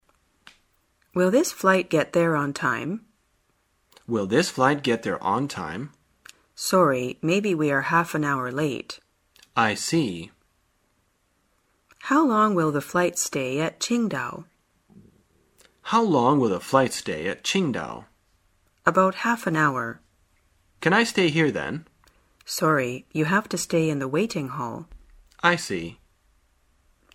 在线英语听力室生活口语天天说 第116期:怎样了解飞行时间的听力文件下载,《生活口语天天说》栏目将日常生活中最常用到的口语句型进行收集和重点讲解。真人发音配字幕帮助英语爱好者们练习听力并进行口语跟读。